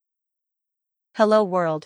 Part 6a. Voice Clip Onset Detection